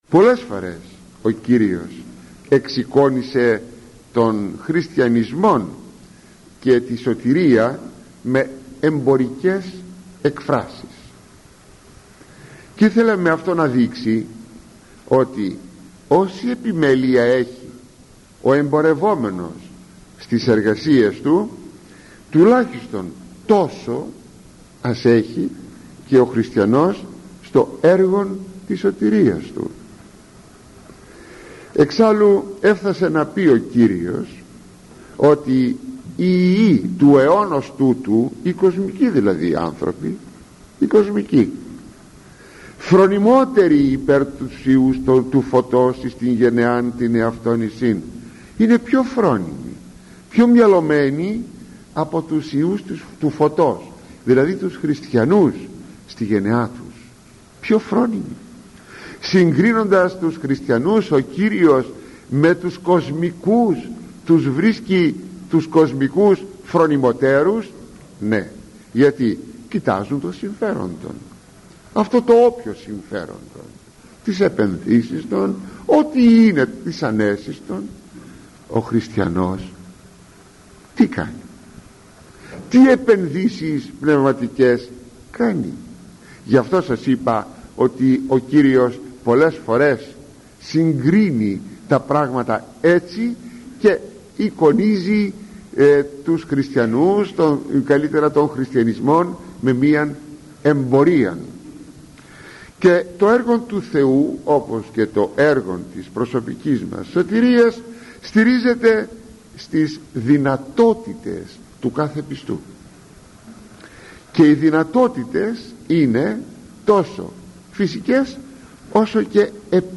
Κυριακή ΙΣΤ’ Ματθαίου: η παραβολή των ταλάντων – ηχογραφημένη ομιλία του Μακαριστού Αρχιμ.